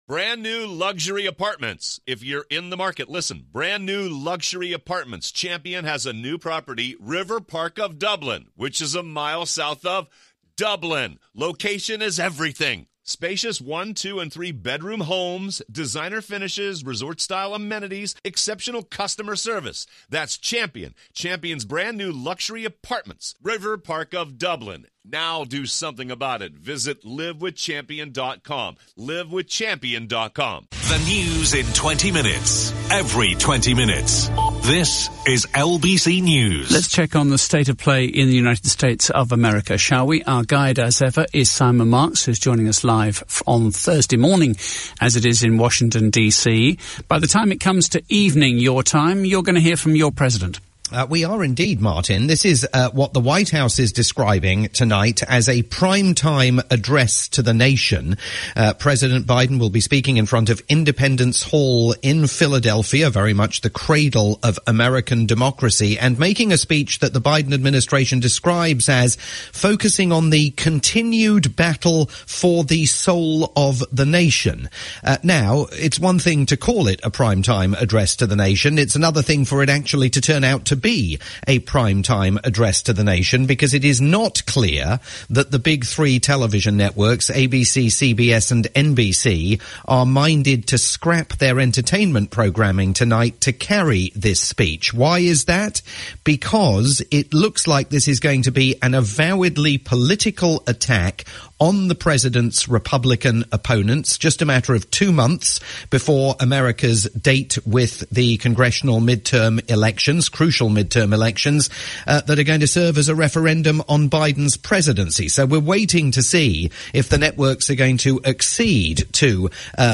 live look at the latest news from the US